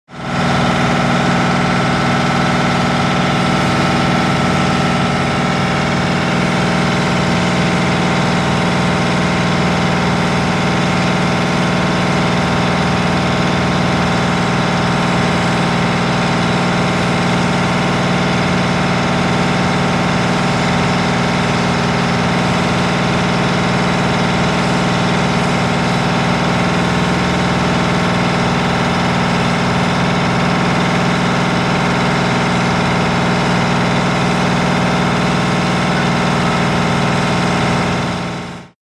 DieselCompresrIdle PE762801
MACHINES - CONSTRUCTION & FACTORY DIESEL COMPRESSOR: INT: Motor idle, slight hiss to mechanism.